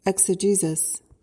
PRONUNCIATION:
(ek-suh-JEE-sis)